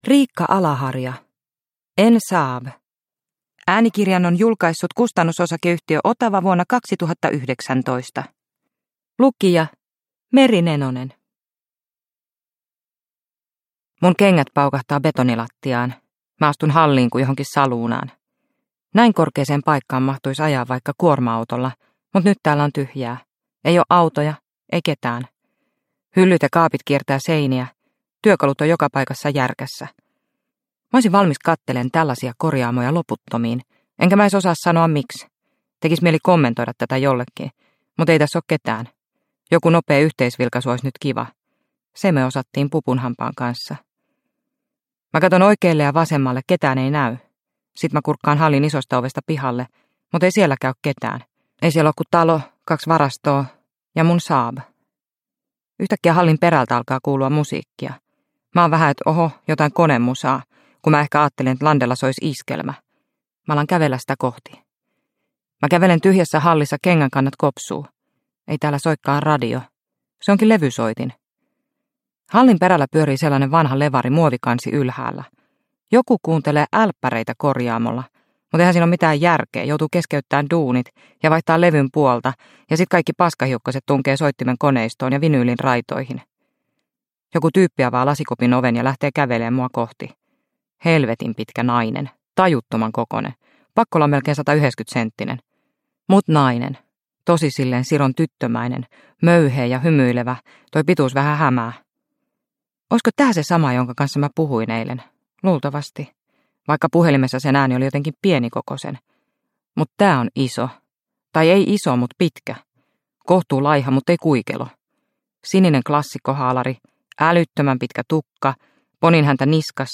En Saab – Ljudbok